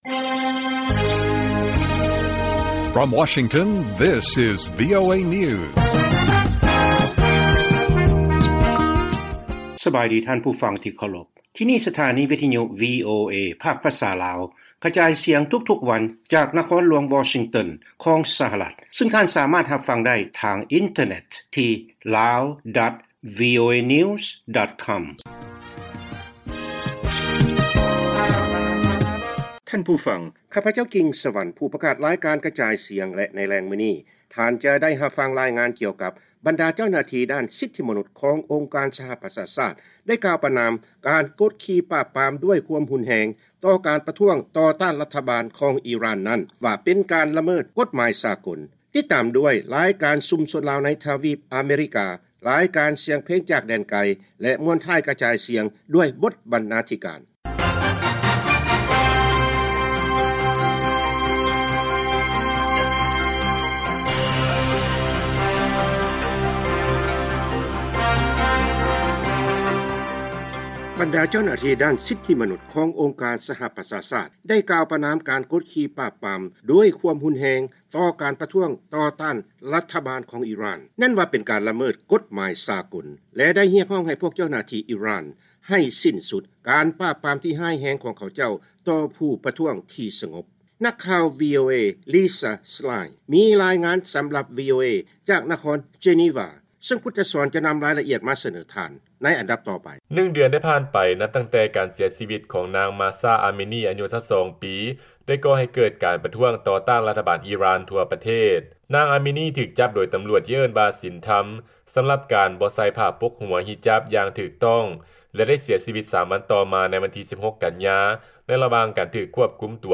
ວີໂອເອພາກພາສາລາວ ກະຈາຍສຽງທຸກໆວັນ ສຳລັບແລງມື້ນີ້ ເຮົາມີ: 1.